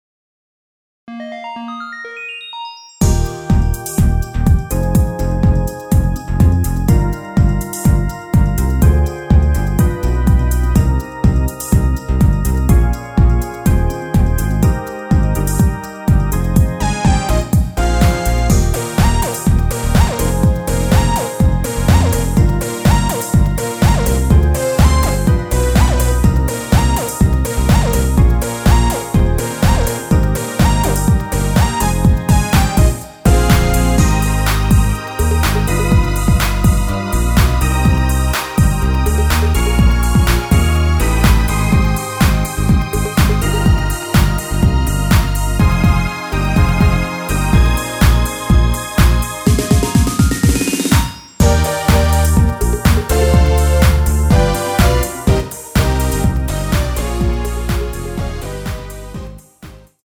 Eb
앞부분30초, 뒷부분30초씩 편집해서 올려 드리고 있습니다.
중간에 음이 끈어지고 다시 나오는 이유는
곡명 옆 (-1)은 반음 내림, (+1)은 반음 올림 입니다.